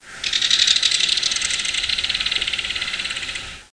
opoyasannij-zimorodok-ceryle-alcyon.mp3